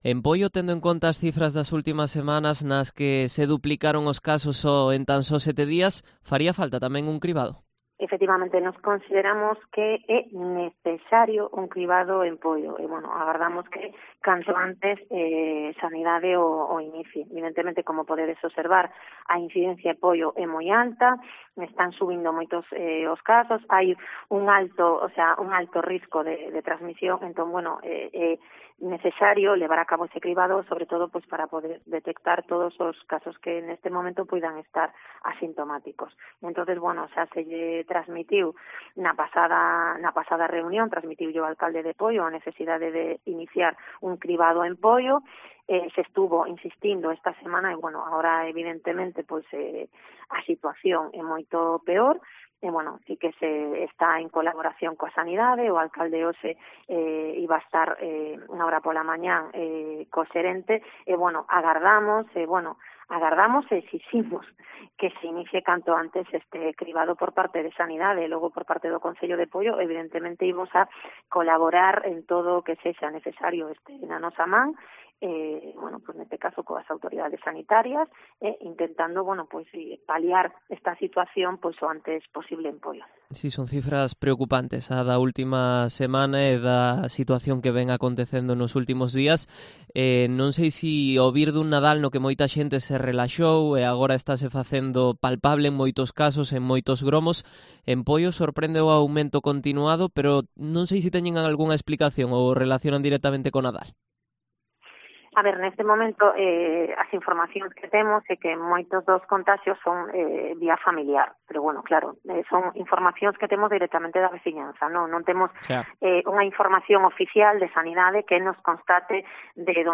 Entrevista completa a Marga Caldas, concelleira de Seguridade Cidadá en Poio